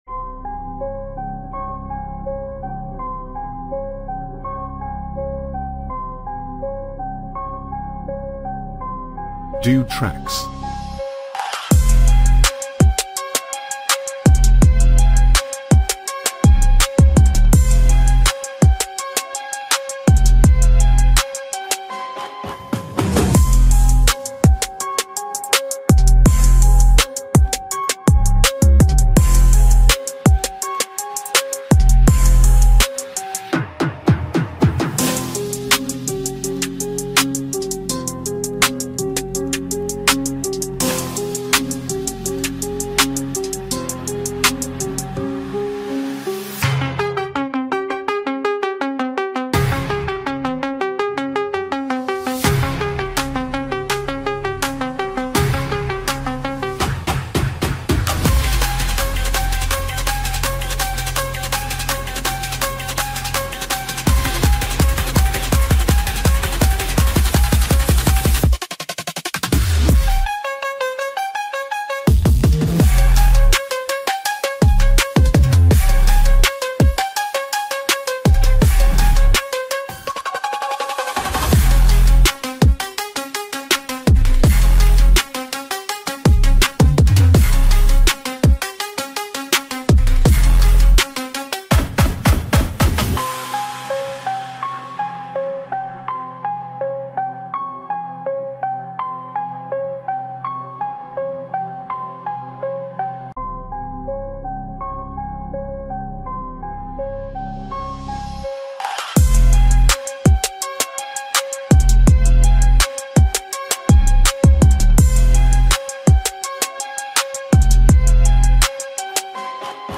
Royalty-Free Hip Hop Beat